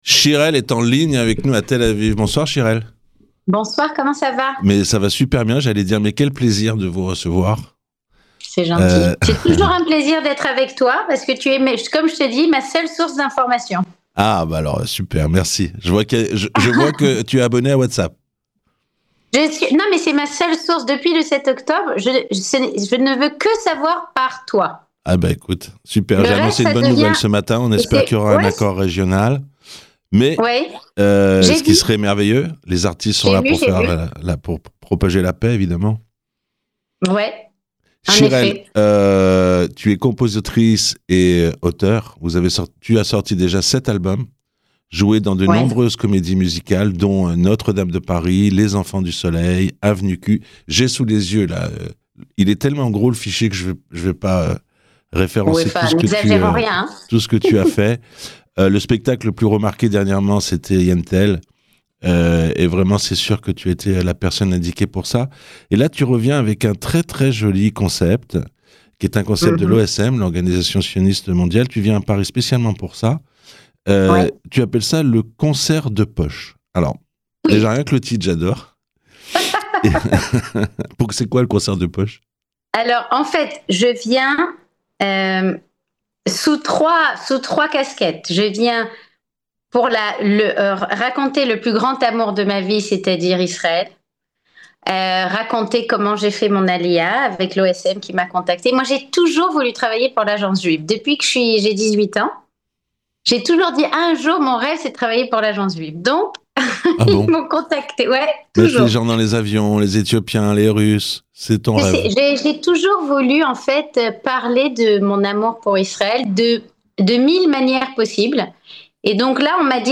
Shirel invitée de Radio Shalom